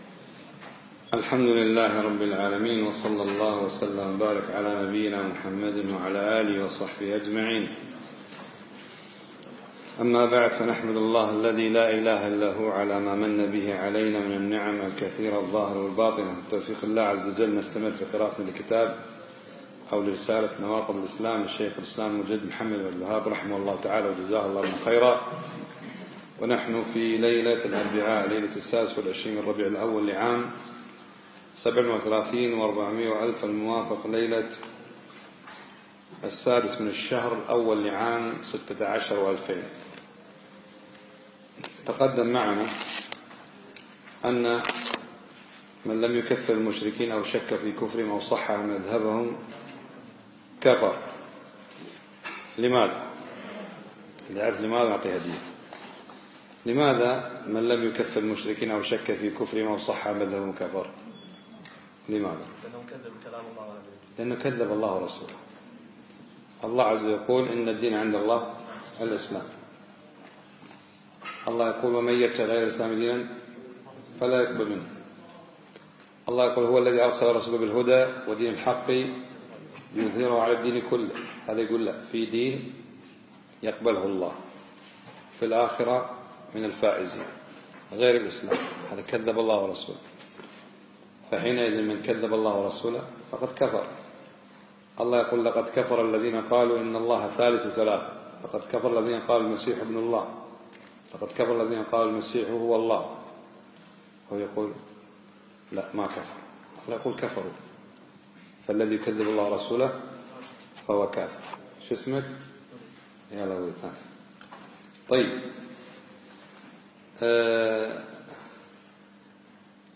يوم الثلاثاء 25 ربيع الأول 1437 الموافق 5 1 2016 مسجد سعد السلطان الفنطاس